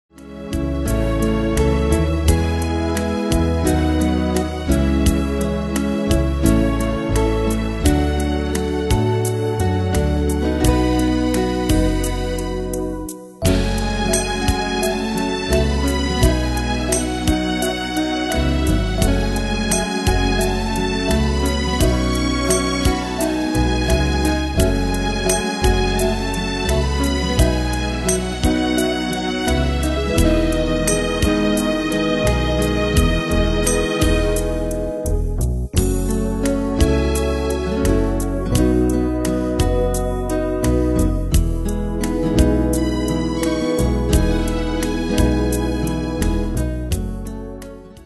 Style: PopFranco Ane/Year: 1996 Tempo: 86 Durée/Time: 4.49
Danse/Dance: Ballade Cat Id.